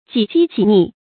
己饥己溺 jǐ jī jǐ nì
己饥己溺发音
成语注音ㄐㄧˇ ㄐㄧ ㄐㄧˇ ㄋㄧˋ